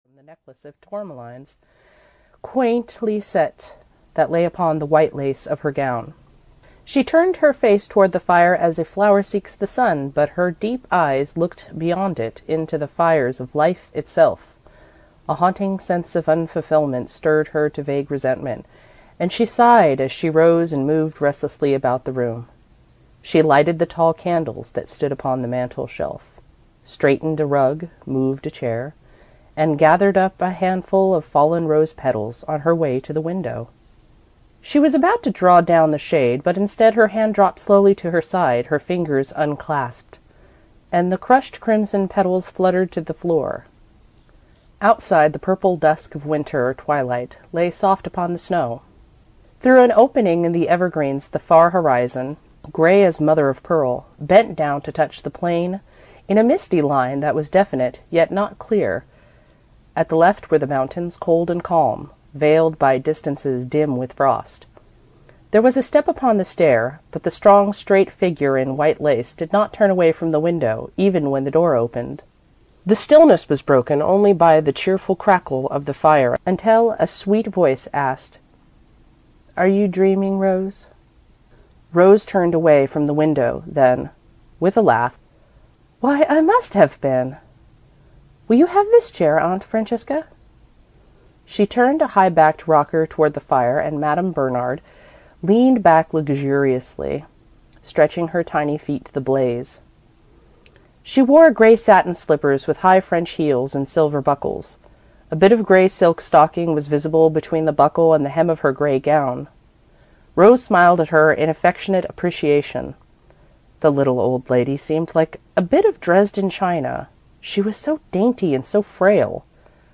Audio knihaOld Rose and Silver (EN)
Ukázka z knihy